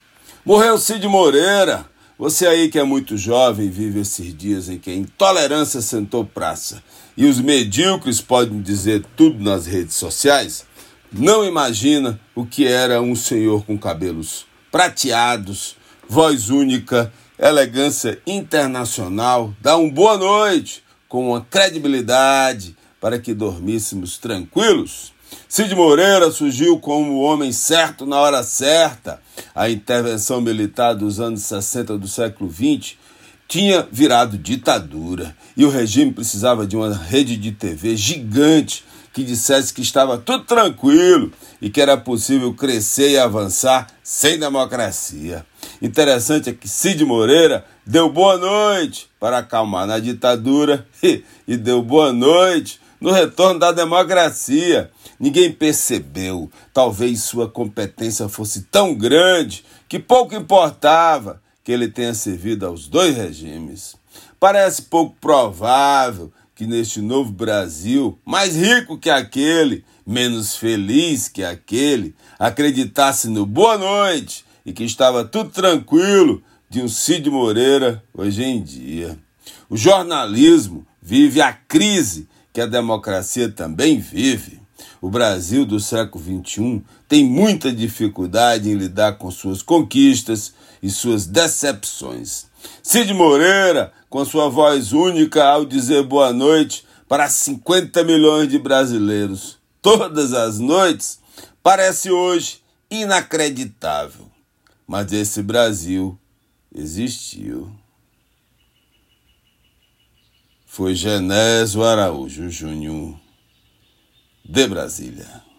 Comentário desta sexta-feira (03/10/24)
direto de Brasília.